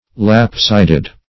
lapsided - definition of lapsided - synonyms, pronunciation, spelling from Free Dictionary Search Result for " lapsided" : The Collaborative International Dictionary of English v.0.48: Lapsided \Lap"sid`ed\, a. See Lopsided .